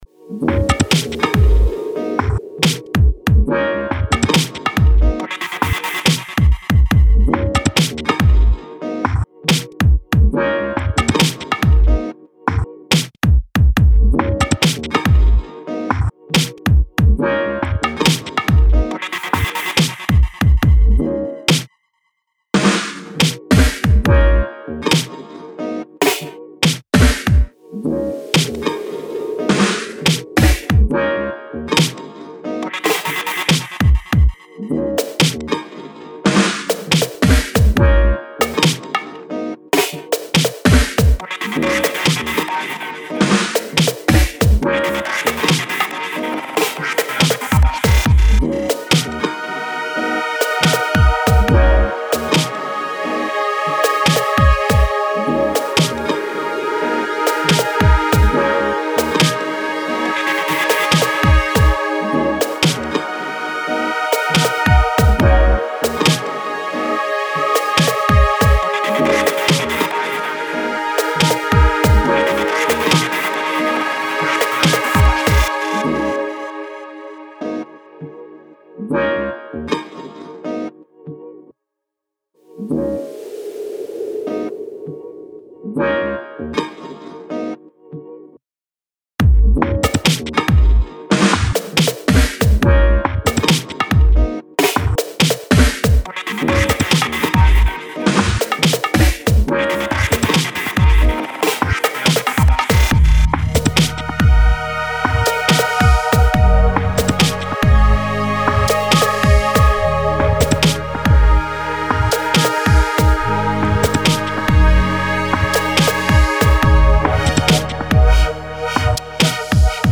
Genres Breaks